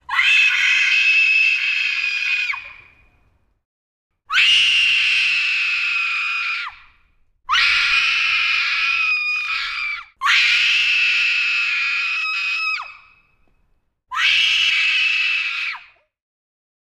Horror screams woman frightened shock scared ext